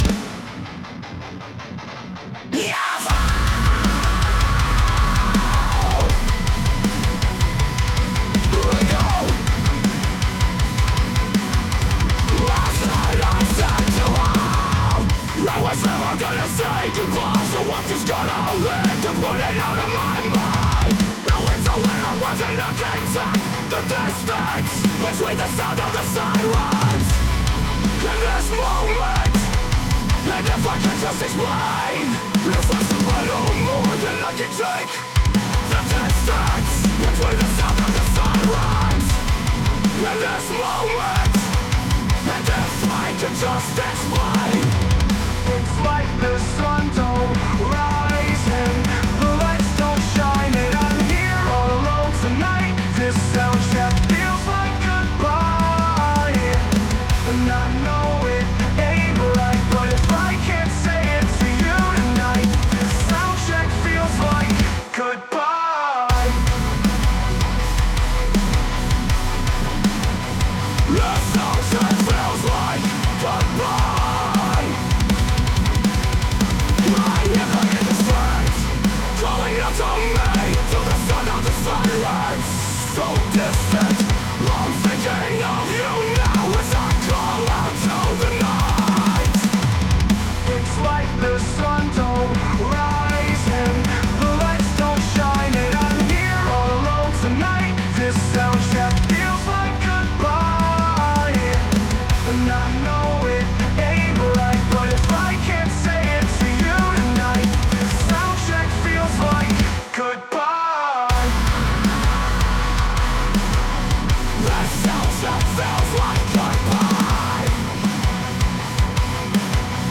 high-energy . aggressive . exiting
Genre: Alternative Metal/Metalcore/Deathcore